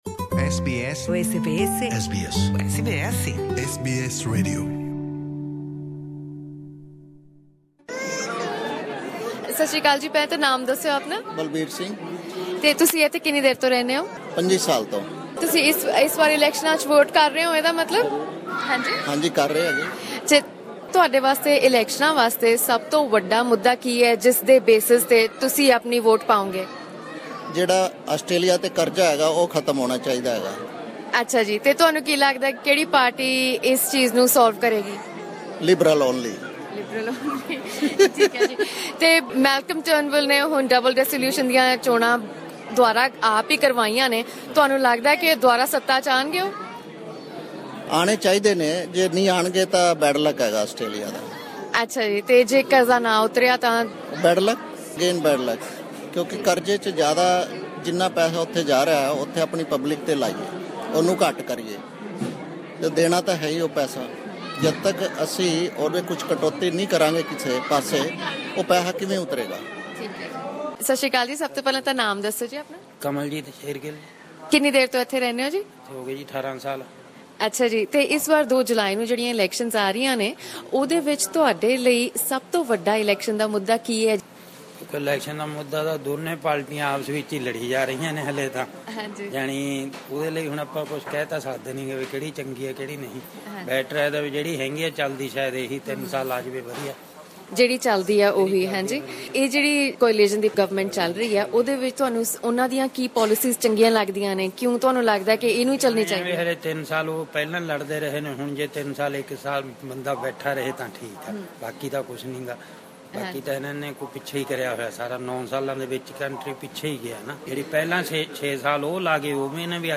Listen to their opinions here...